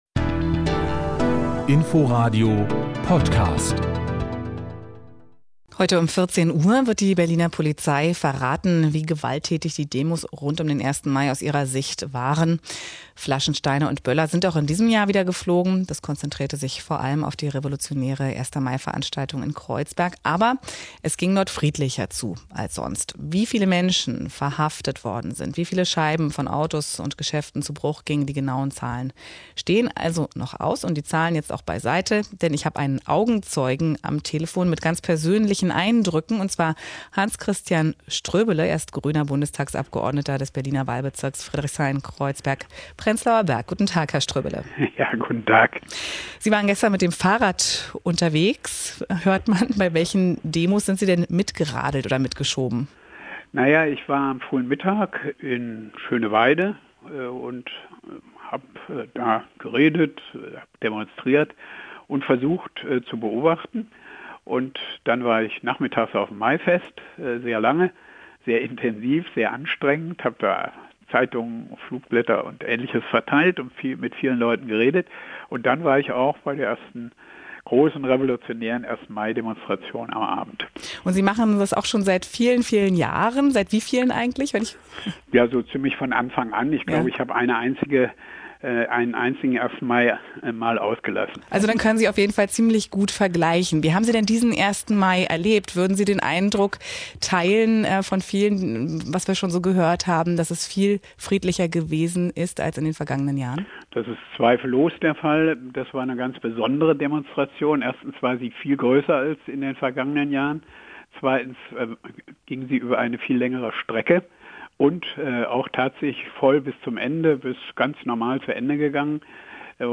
Interview im RBB-Inforadio: "Ströbele: Wenn die Polizei friedlich bleibt..."